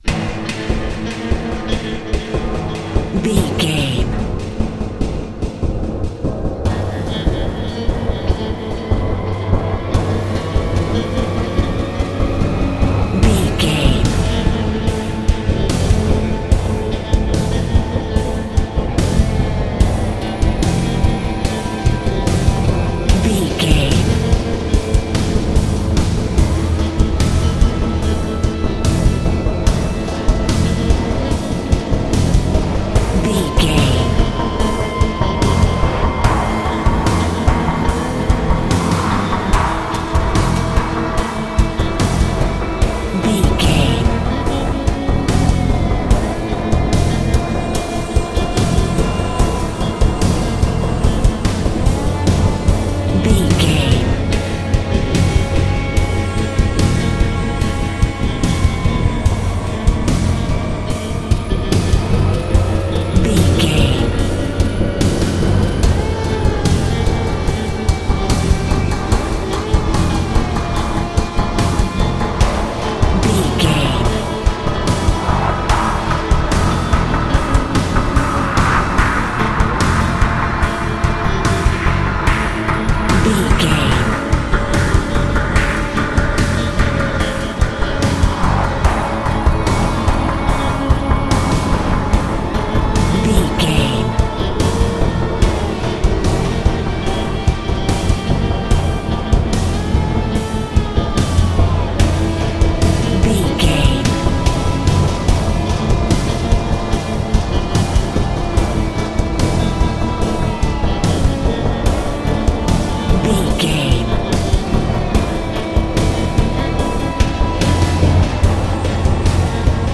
Thriller
Aeolian/Minor
synthesiser
drum machine
electric guitar
ominous
dark